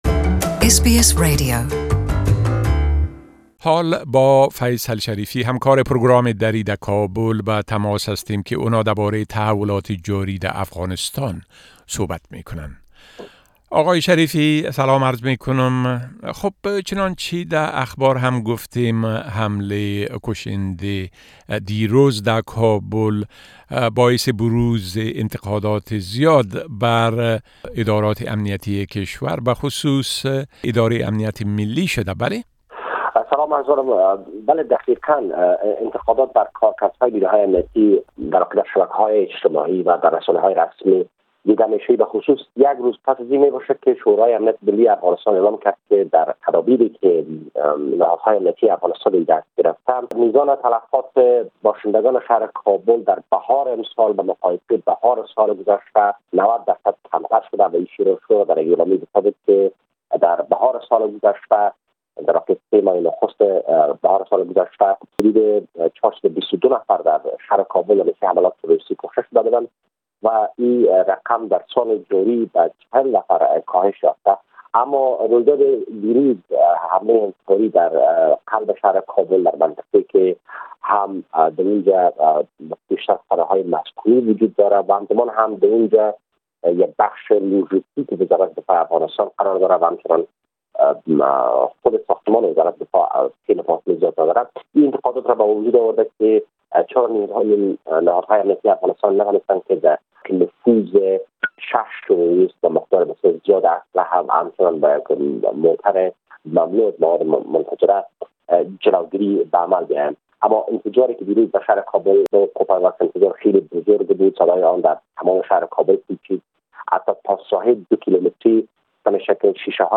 Our correspondent's report from Kabul can be heard here.